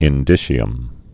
(ĭn-dĭshē-əm)